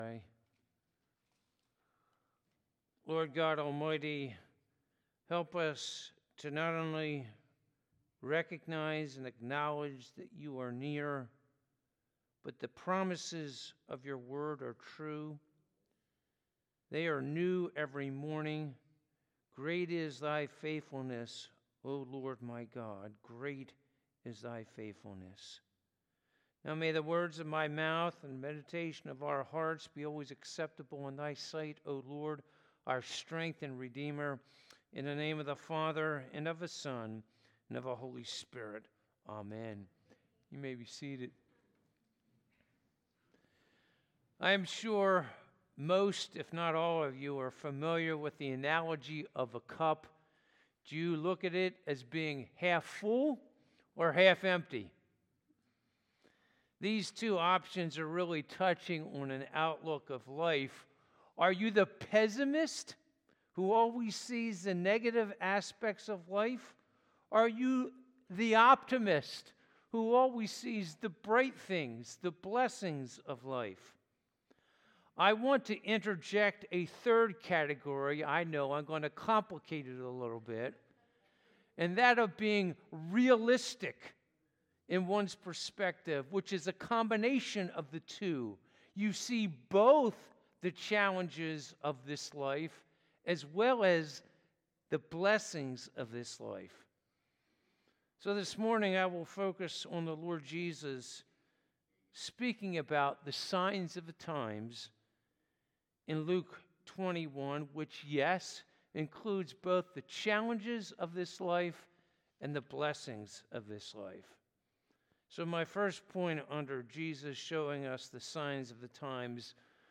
Passage: Luke 21:10-24 Service Type: Sunday Morning